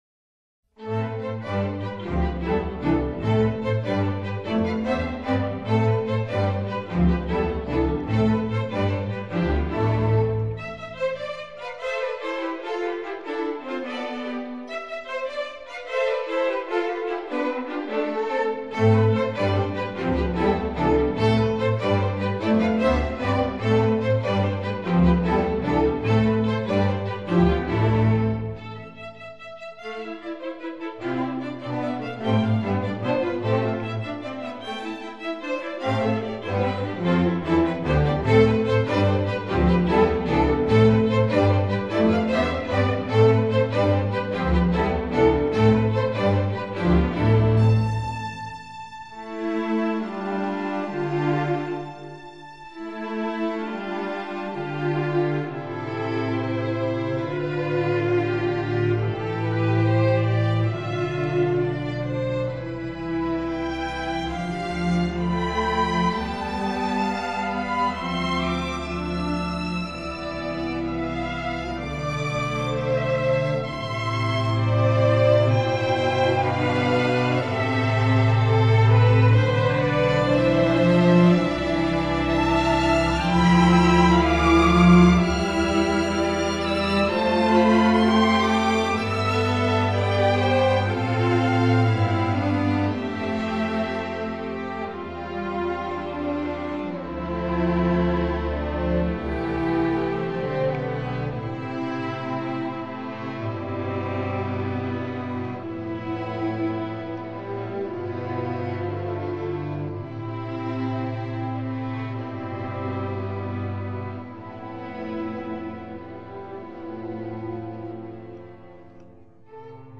Digital sheet music for string orchestra
instructional